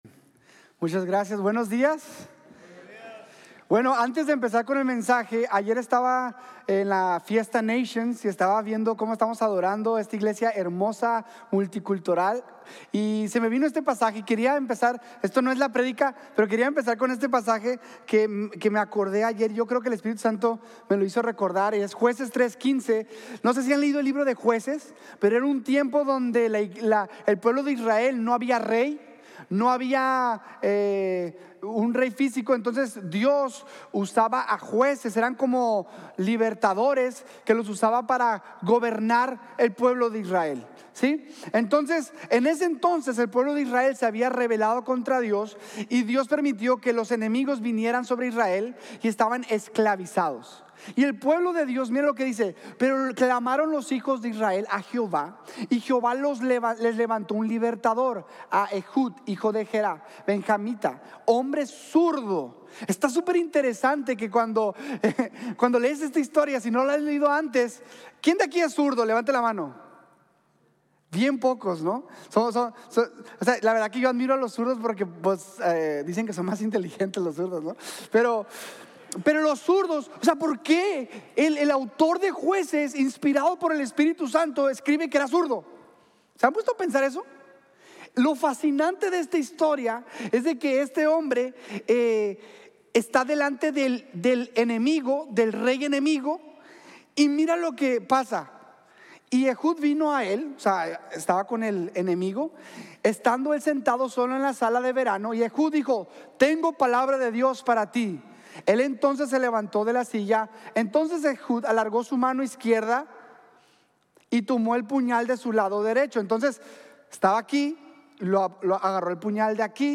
La importancia de la iglesia que envía | Sermón | Grace Bible Church